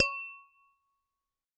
标签： 不倒翁 Freesound上 循环 雄蜂
声道立体声